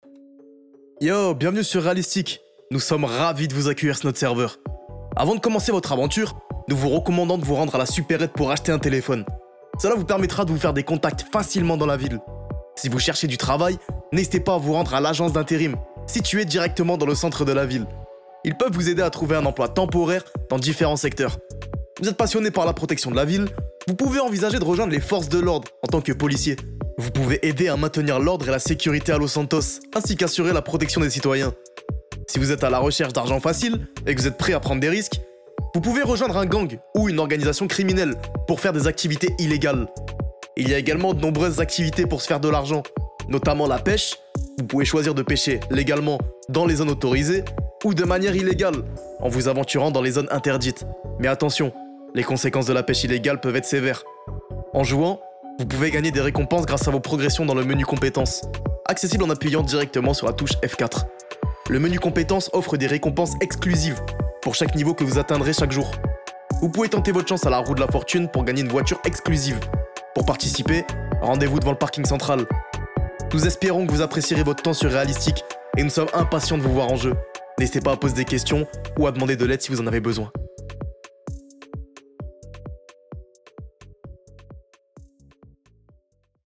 voix off realistic.mp3
voixoffrealistic.mp3